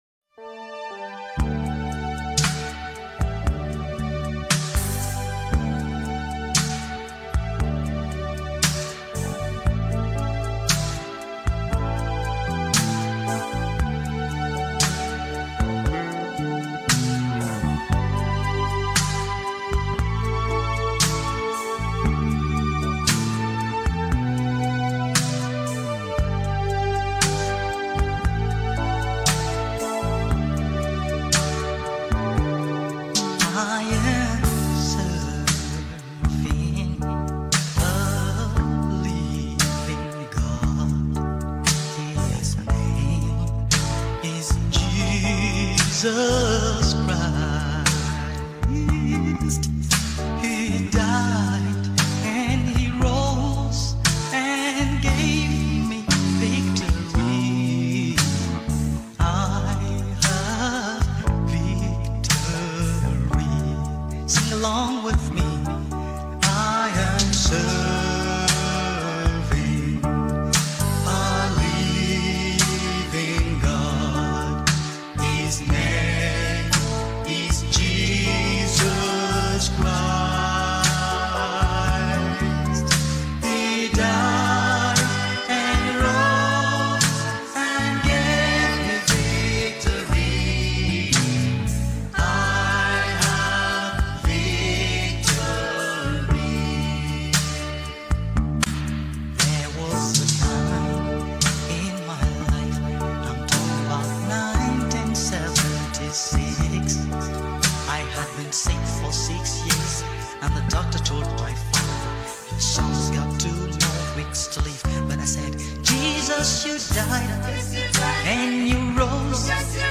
March 19, 2025 Publisher 01 Gospel 0